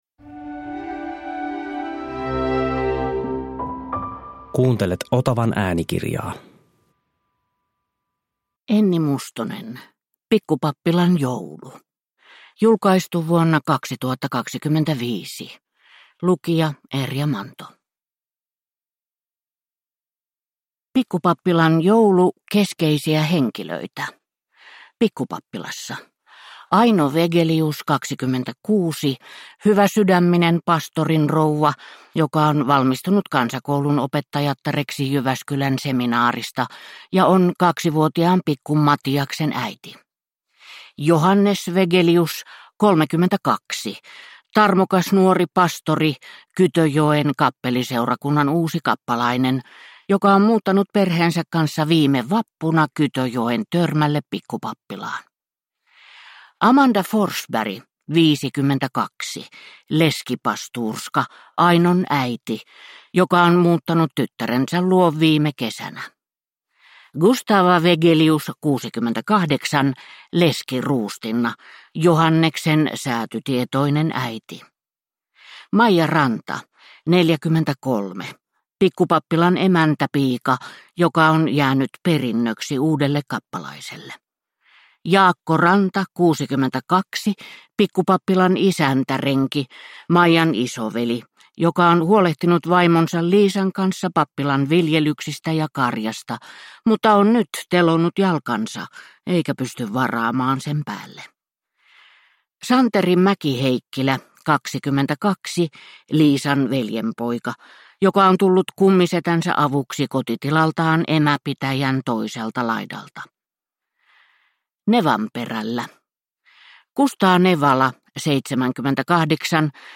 Pikkupappilan joulu – Ljudbok